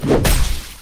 🌲 / foundry13data Data modules soundfxlibrary Combat Single Melee Hit
melee-hit-5.mp3